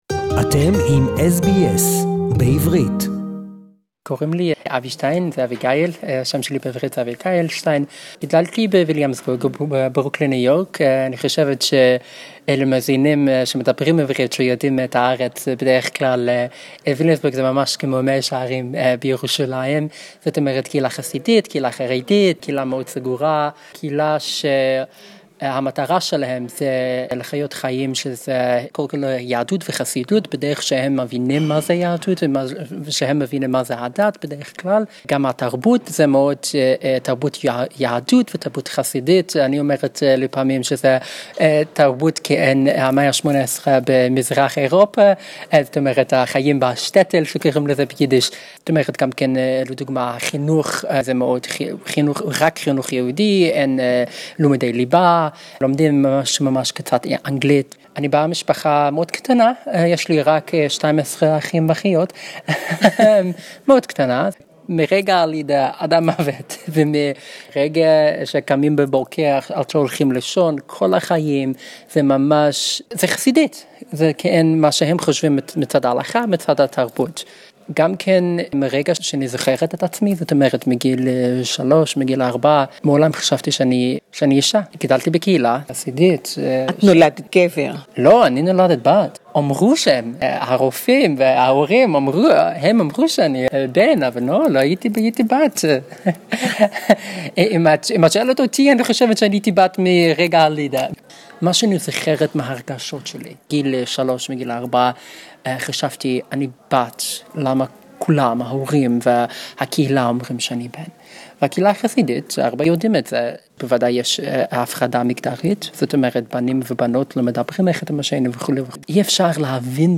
(Hebrew interview)